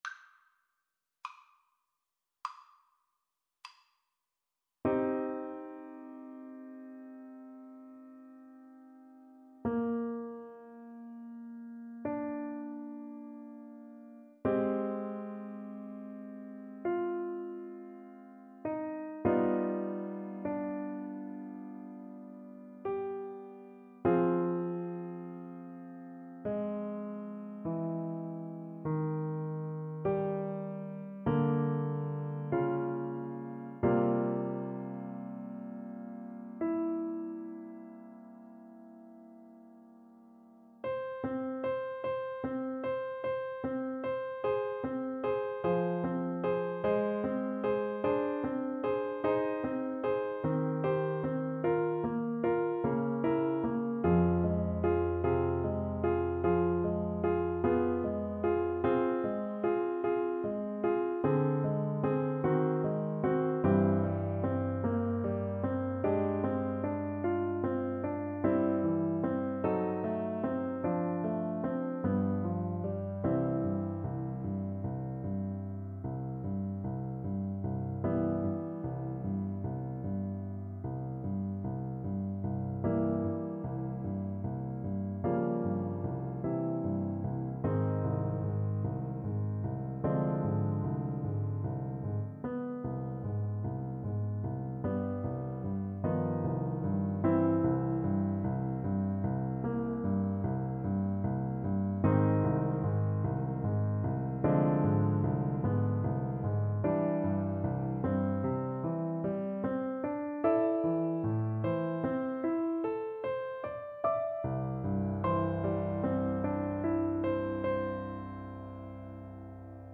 ~ = 100 Lento =50
4/4 (View more 4/4 Music)
Classical (View more Classical Trumpet Music)